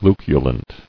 [lu·cu·lent]